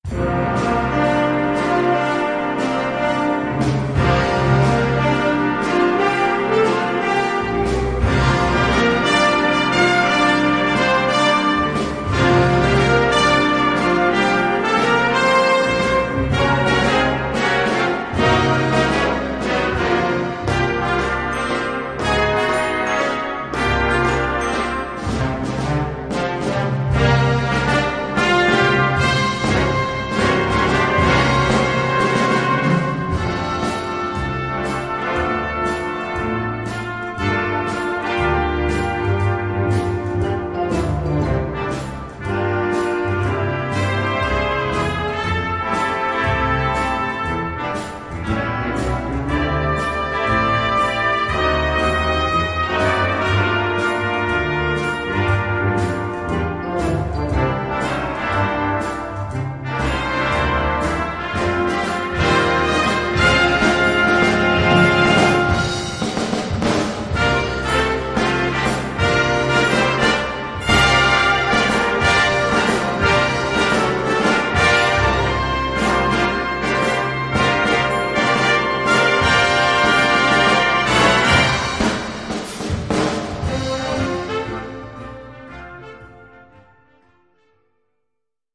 Gattung: Evergreen
Besetzung: Blasorchester